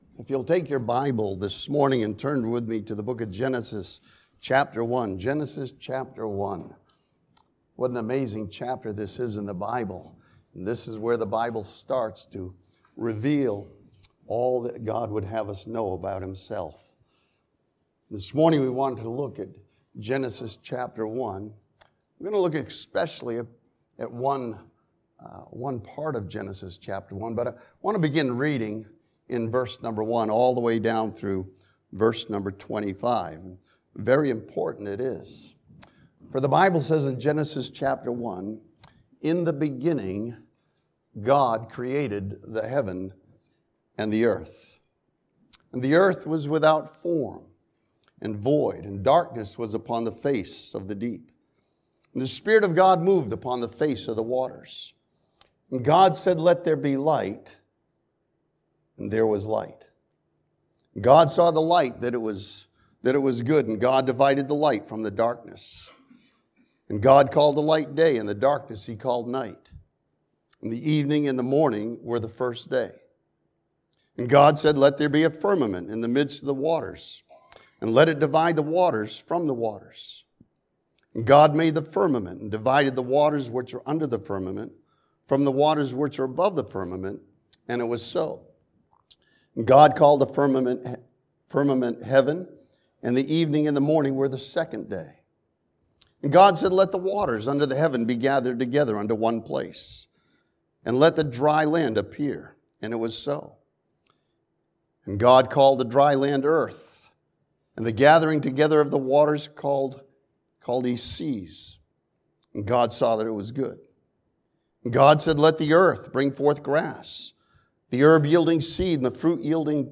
This sermon from Genesis 1 studies the Biblical truth that man was created in the image of God.